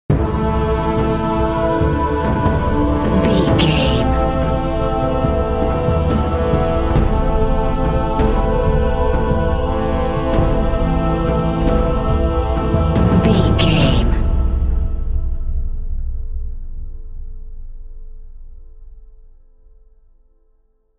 Aeolian/Minor
synthesiser
drum machine
horns
percussion
ominous
suspense
haunting
creepy